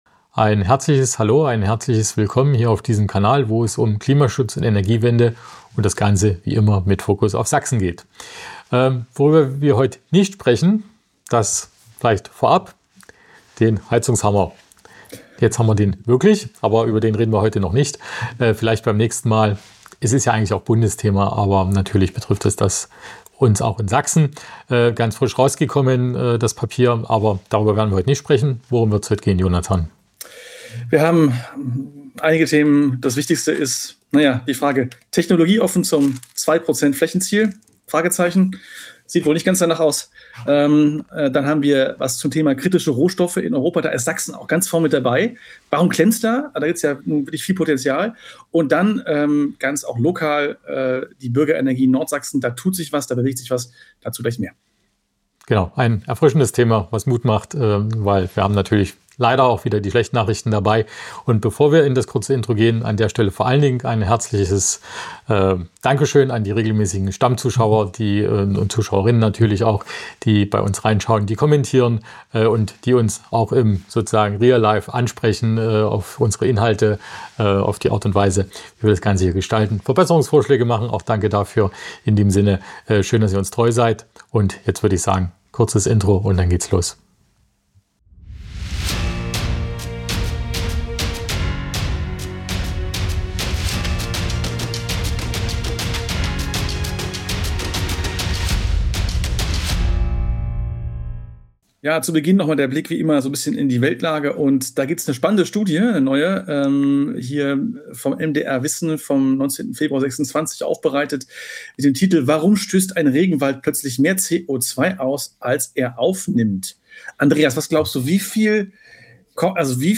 Außerdem schauen wir darauf, wie es um die Versorgung mit kritischen Rohstoffen in Europa steht – und welche Rolle Sachsen dabei spielt. Zum Abschluss gibt es ein motivierendes Interview mit Akteurinnen und Akteuren aus Nordsachsen, die eine Bürgerenergiegemeinschaft für Nordsachsen auf den Weg bringen wollen.